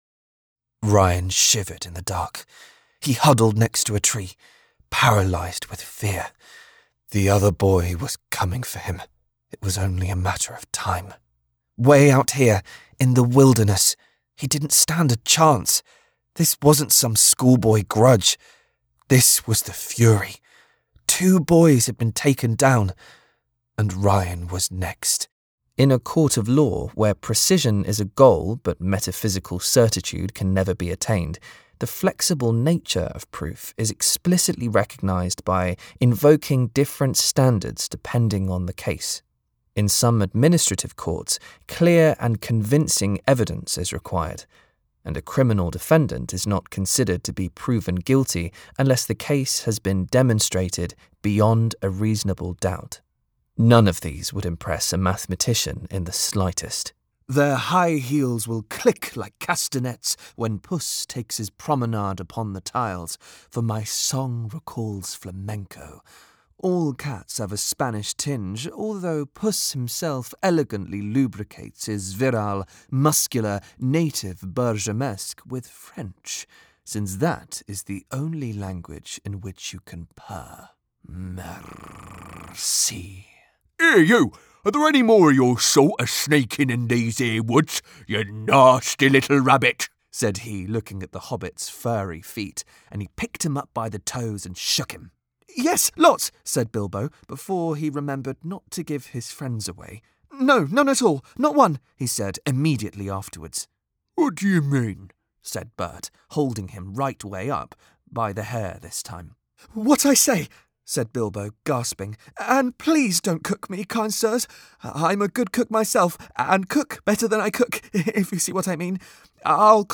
Audiobook Showreel
His approachable, youthful British RP voice has roots in Yorkshire, East Midlands, and Essex.
Male
Neutral British
Youthful